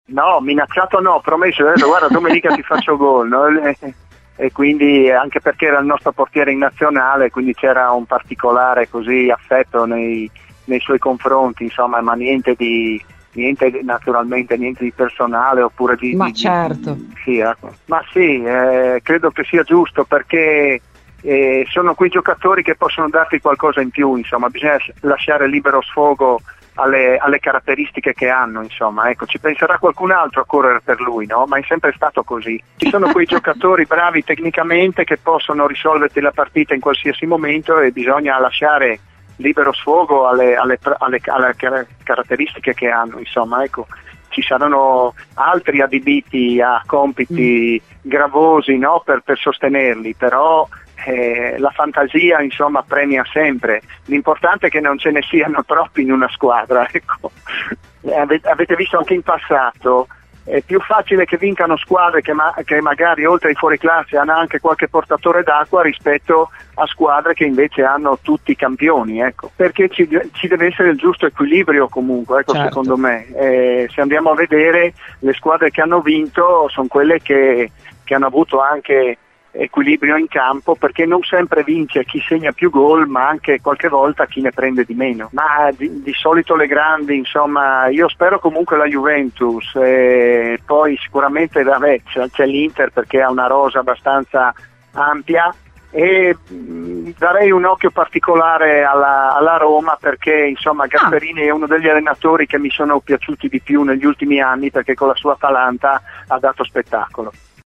Gigi De Agostini dice la sua sul Derby d'Italia, in programma domani alle 18. In esclusiva a Radio Bianconera, durante RBN cafe, l'ex difensore, doppio ex, inquadra così il match: "Ne ho giocate tante di sfide tra Juve e Inter.